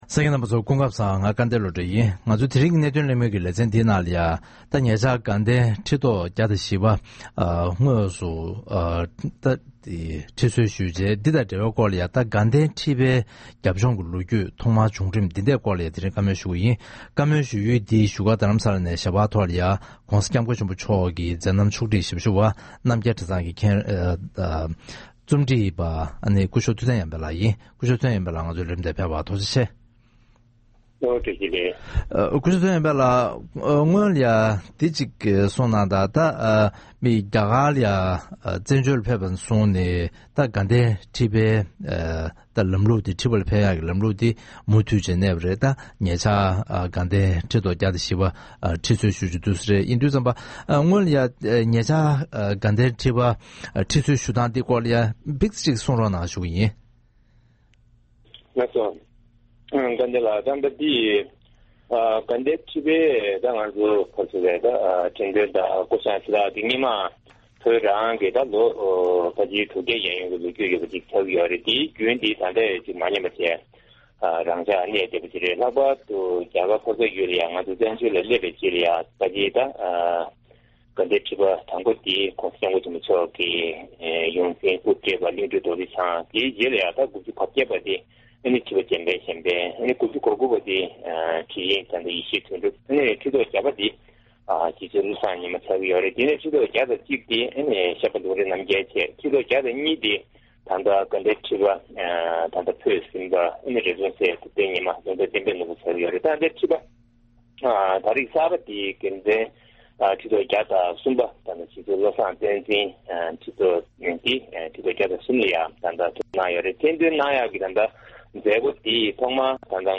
༄༅། །ཐེངས་འདིའི་གནད་དོན་གླེང་མོལ་གྱི་ལེ་ཚན་ནང་། ཤར་པ་ཆོས་རྗེ་རིན་པོ་ཆེ་རྗེ་བཙུན་བློ་བཟང་བསྟན་འཛིན་མཆོག་དགའ་ལྡན་ཁྲི་པར་དངོས་སུ་ཁྲི་གསོལ་ཞུས་ཡོད་པ་དང་བསྟུན། དགའ་ལྡན་ཁྲི་པའི་འབྱུང་རིམ་གྱི་སྐོར་ལ་གླེང་མོལ་ཞུས་པ་ཞིག་གསན་རོགས་གནང་།